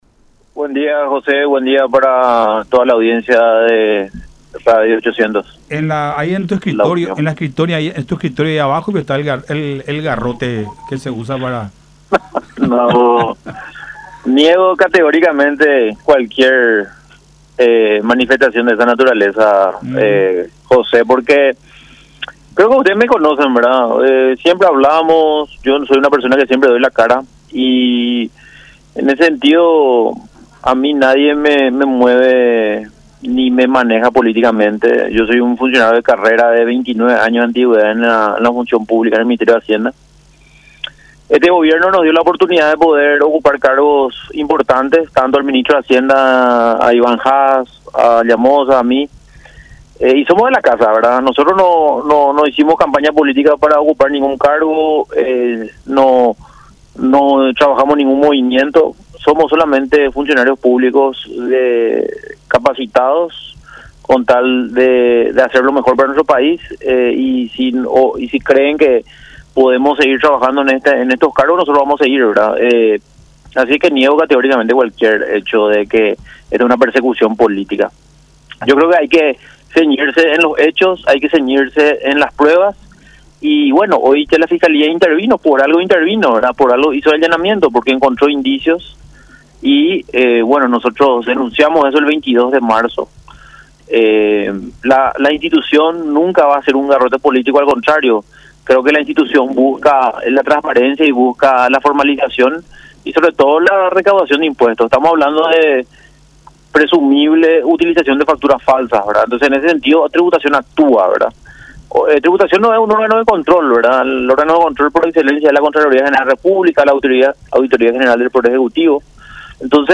Queremos seguir haciendo lo mejor para nuestro país y si creen que podemos seguir trabajando en estos cargos, vamos a seguir”, manifestó Orué en conversación con Todas Las Voces por La Unión, negando llevar adelante una “persecución política” a pedido del presidente Mario Abdo y del vicepresidente Hugo Velázquez.